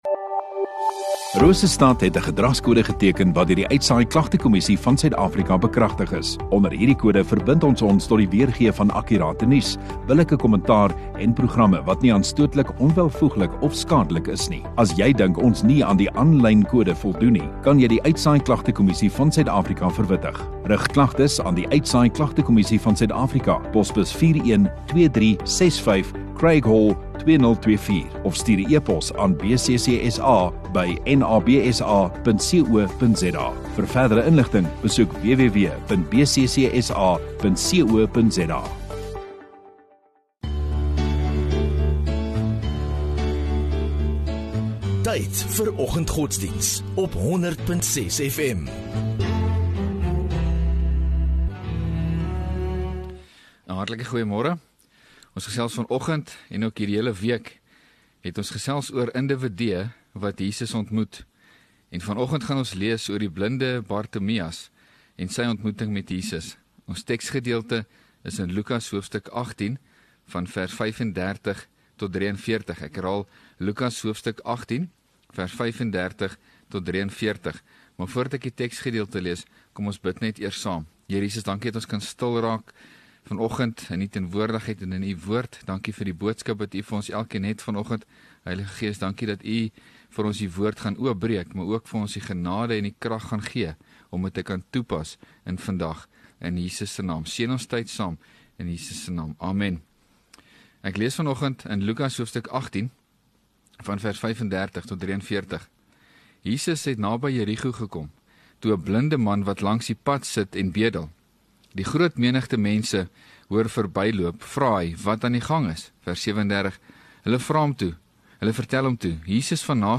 12 Sep Vrydag Oggenddiens